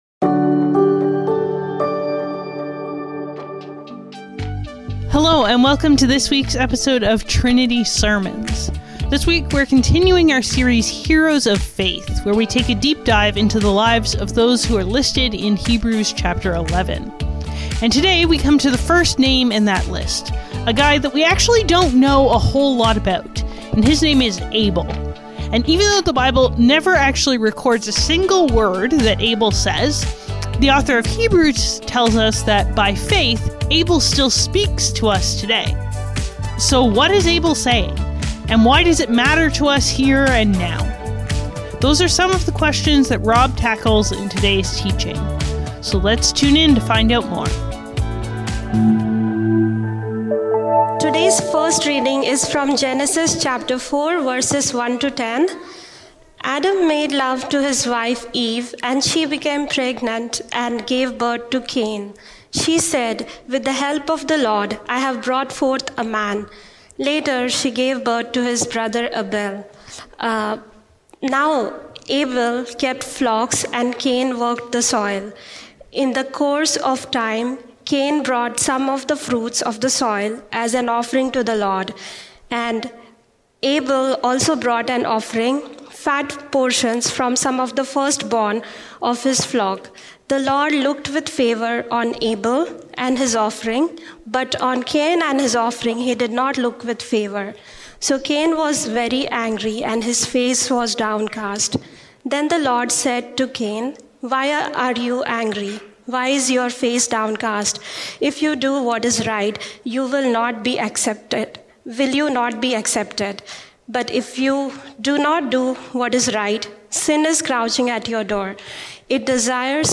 Trinity Streetsville - Abel: Faith that Speaks | Heroes of Faith | Trinity Sermons - Archive FM